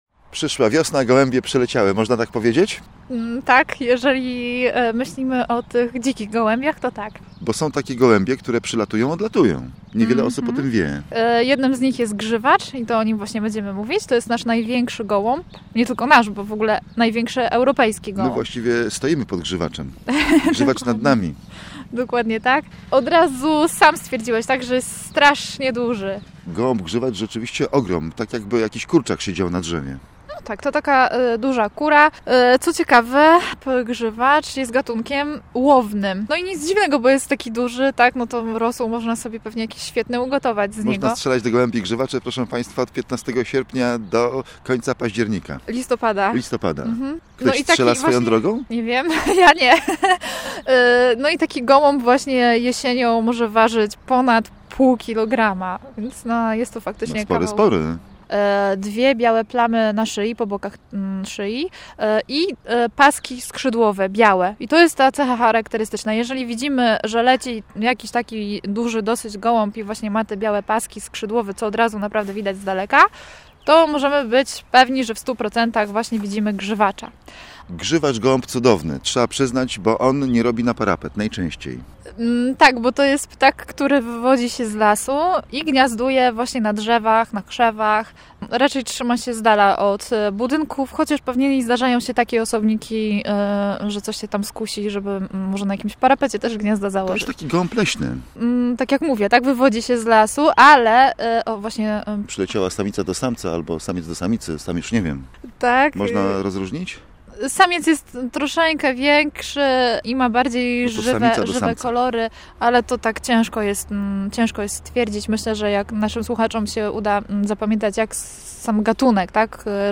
Rozpoczynając audycję okazuje się, że w miejscu ptaków w gnieździe siedzi kot.